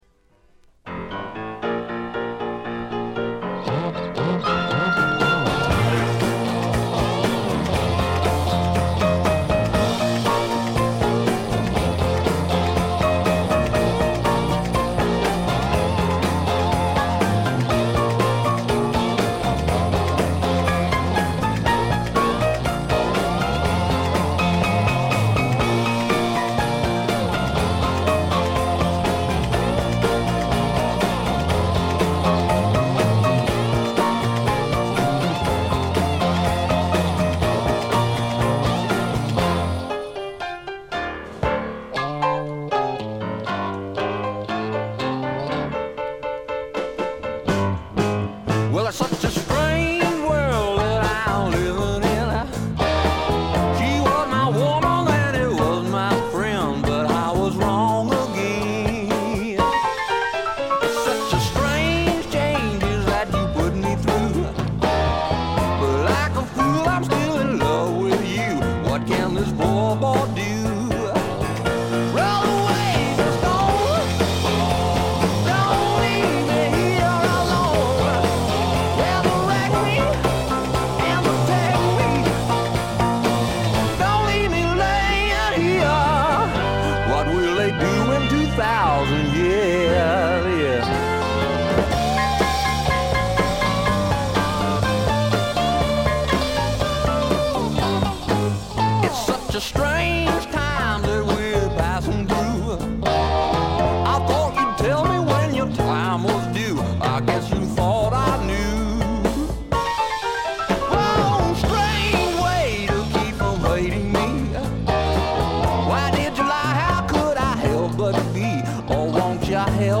微細なチリプチ程度。
内容はいうまでもなくスワンプ風味の効いた素晴らしいシンガー・ソングライター・アルバム。
試聴曲は現品からの取り込み音源です。